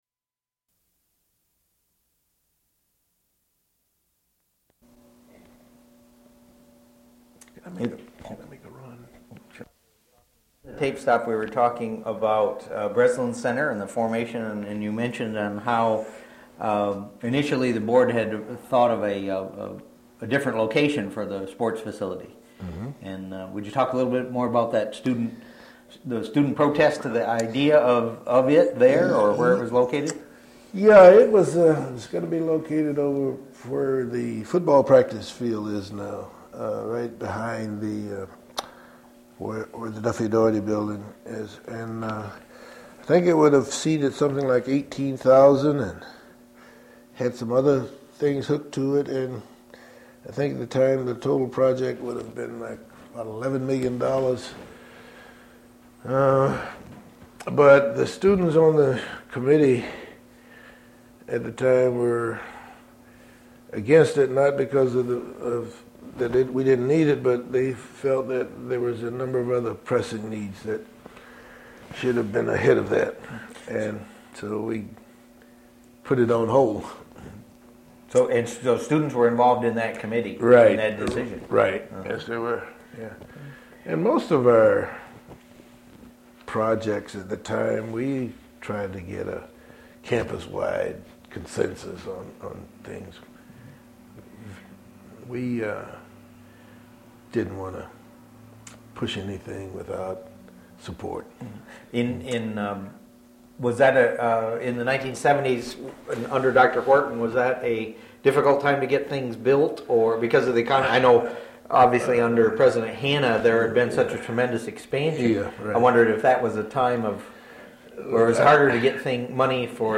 Interview with Blanche Martin on March 31, 2000- part 2/3
Date: March 31, 2000 Format: Audio/mp3 Original Format: Audiocassettes Resource Identifier: Blanche_Martin_Intv_t1s2.mp3 Collection Number: UA 3 Language: English Rights Management: Educational use only, no other permissions given.